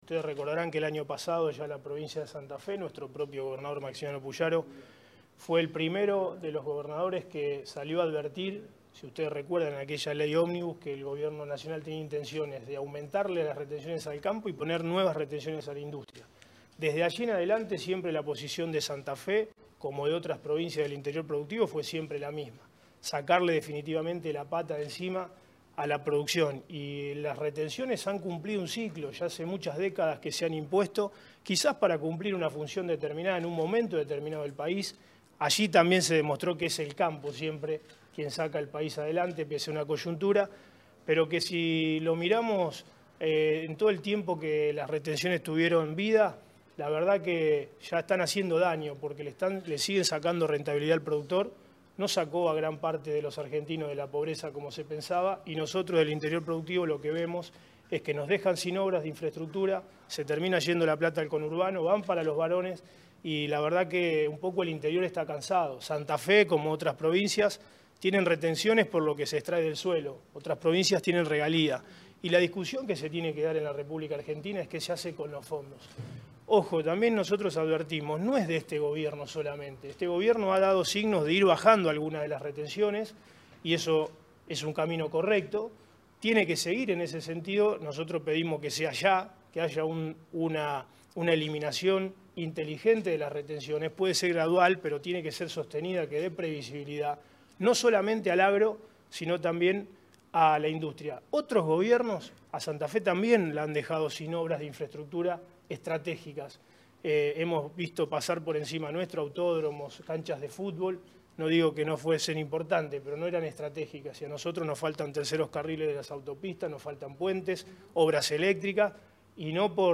Declaraciones del ministro Puccini